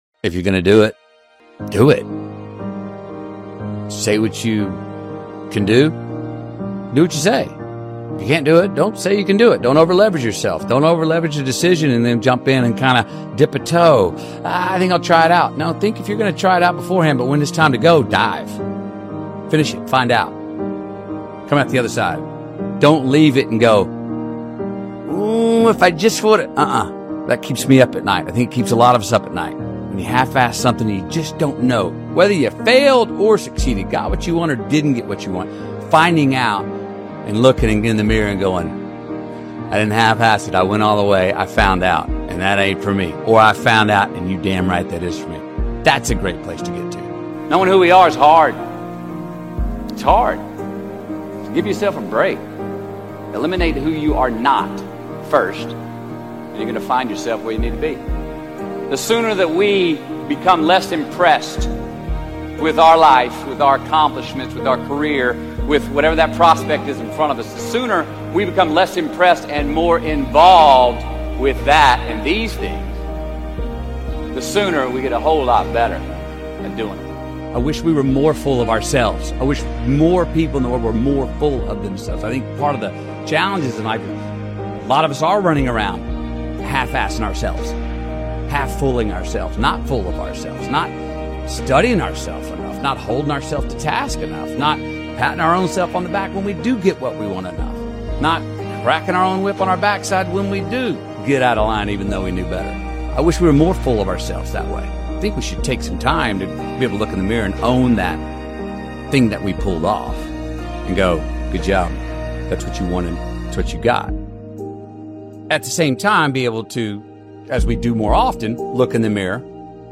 Matthew McConaughey – If you say you're gonna do it, go all in motivational speech